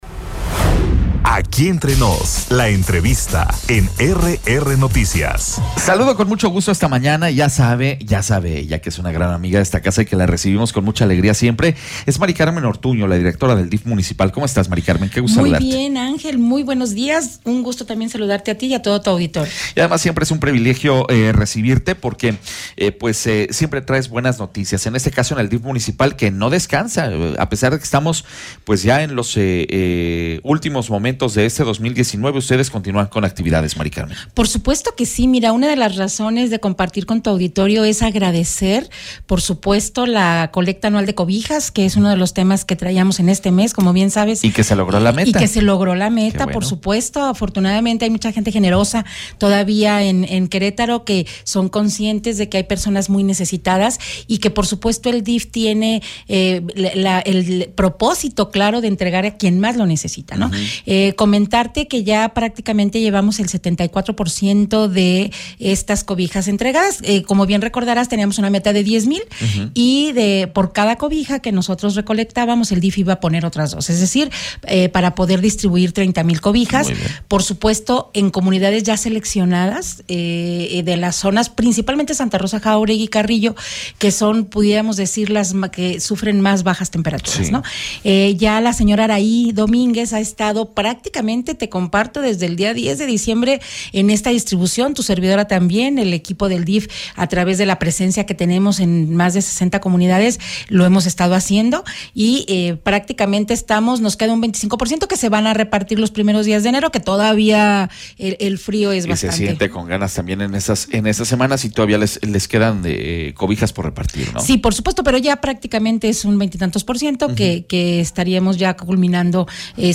ENTREVISTA-MARICARMEN-ORTUÑO-DIRECTORA-DEL-DIF-MUNICIPAL.mp3